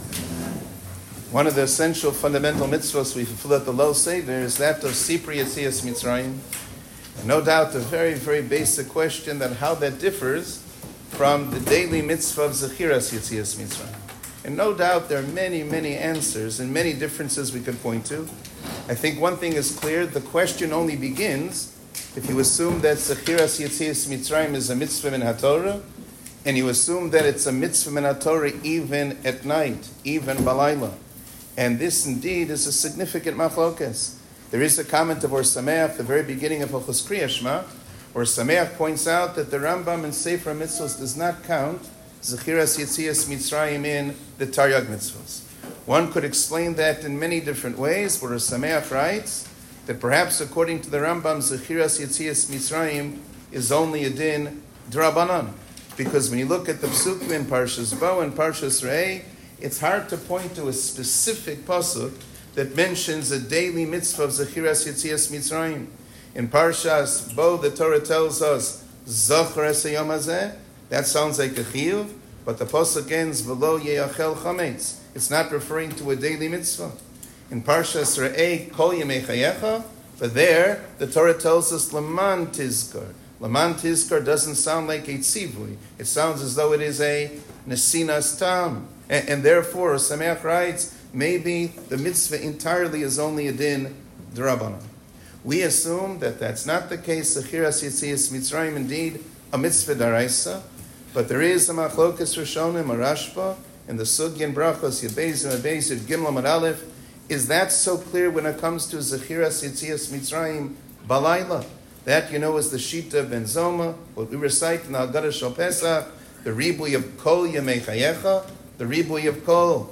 פסח - Q&A